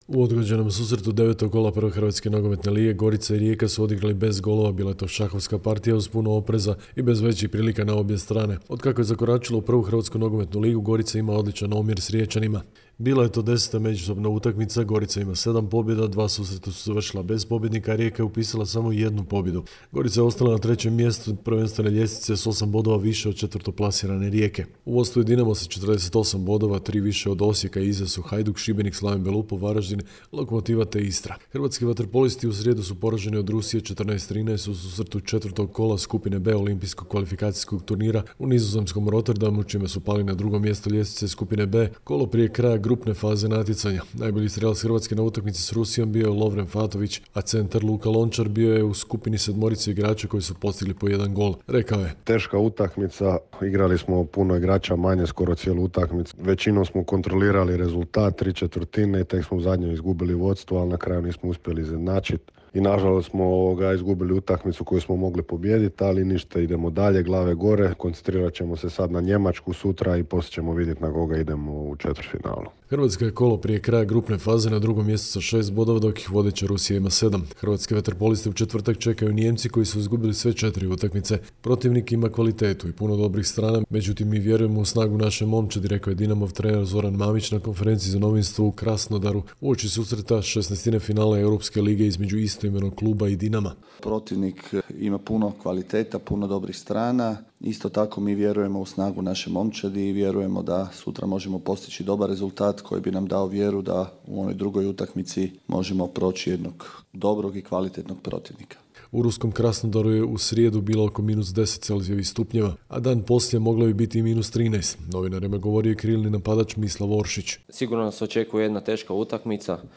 Sportske vijesti iz Hrvatske, 18.2.2021.